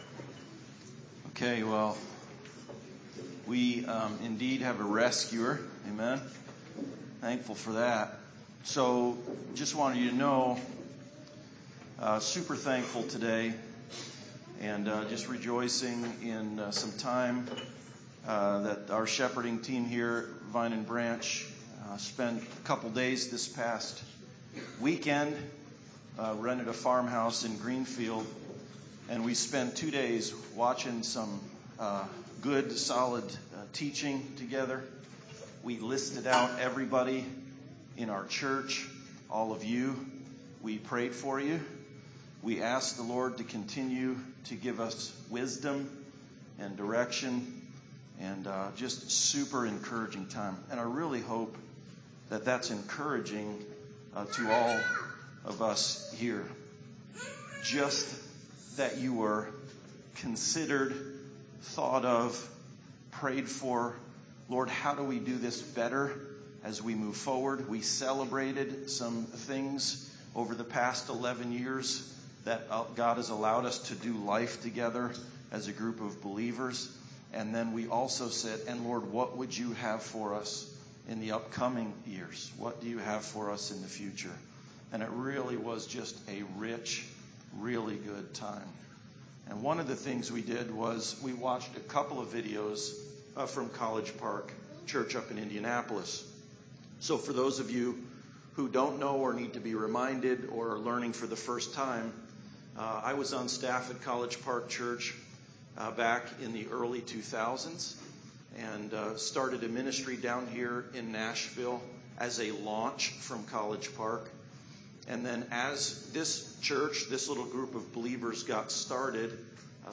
Core Values Passage: Colossians 1:15-29 Service Type: Sunday Service